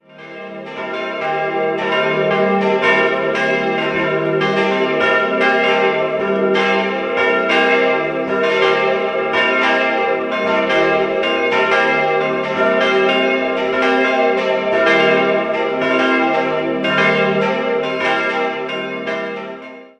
Die heutige Pfarrkirche wurde in den Jahren 1895/96 errichtet und gehört zu den besonders gelungenen Beispielen für neugotischen Kirchenbau in Oberbayern. Idealquartett: e'-g'-a'-c'' Die drei kleineren Glocken wurden 1952 von der Gießerei Ulrich in Kempten gegossen, die große ist historisch und stammt möglicherweise von Johannes Weber (Augsburg) aus dem Jahr 1741.